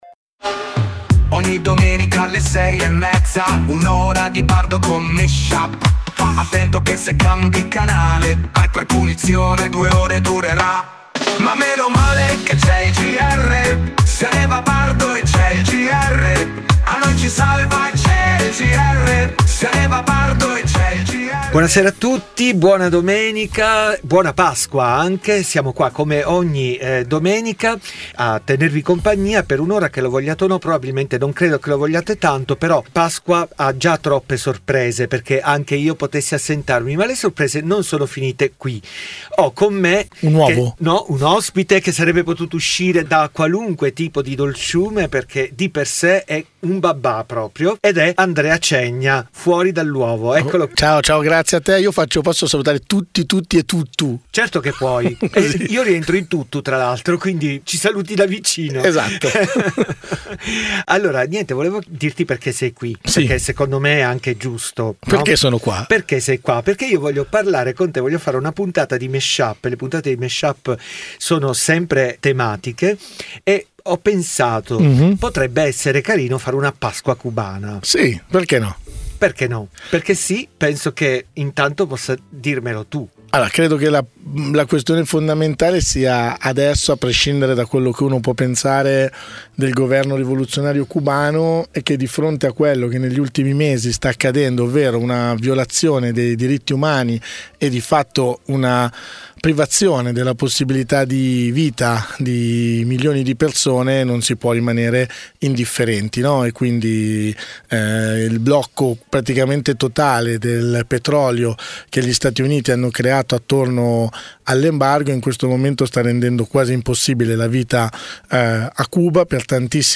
dj set tematico di musica e parole